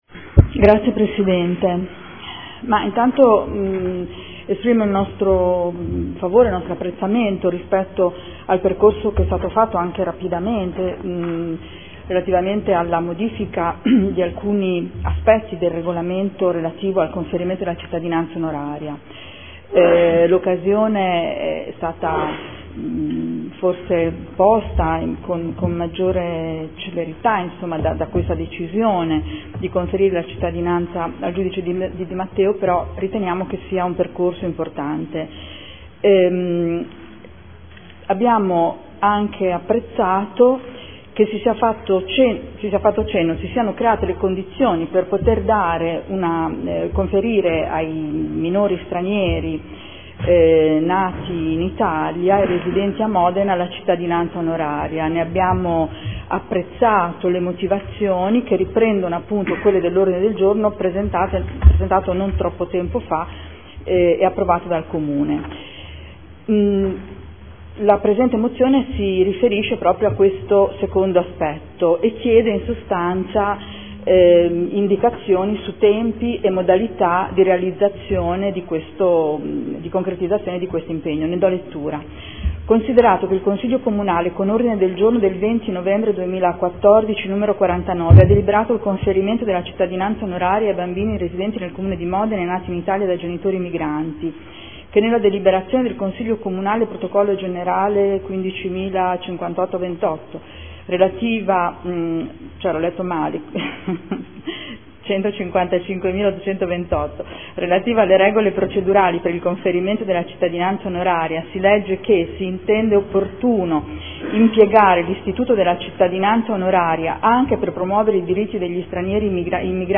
Seduta del 05/02/2015 Presenta Odg 16226.